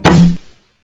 sol_reklam_link sag_reklam_link Warrock Oyun Dosyalar� Ana Sayfa > Sound > Weapons > TAC15 Dosya Ad� Boyutu Son D�zenleme ..
WR_Fire.wav